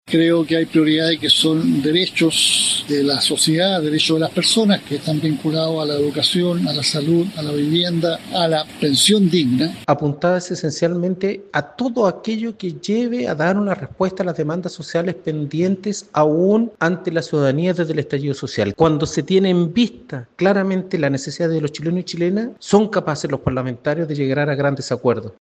Así lo plantearon el presidente del PC, Lautaro Carmona, y el timonel del Partido Radical, Leonardo Cubillos.